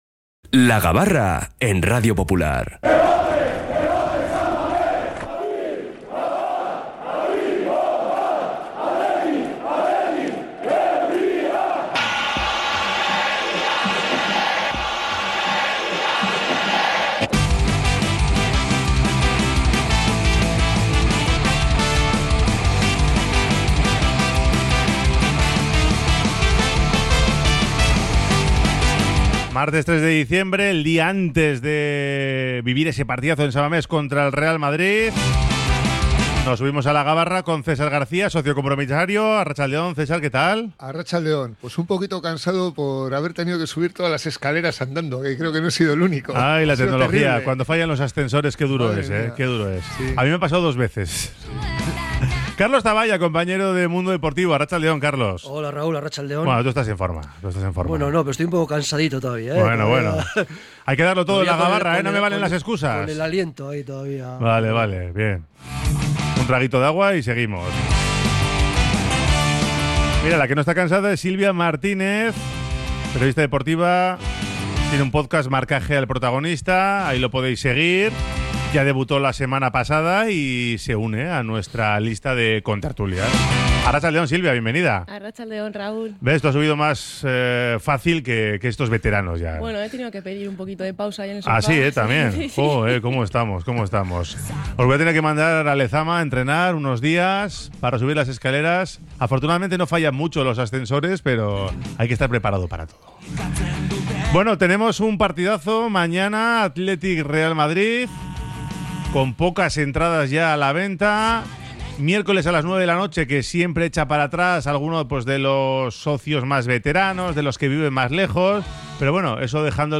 La Gabarra: La tertulia diaria del Athletic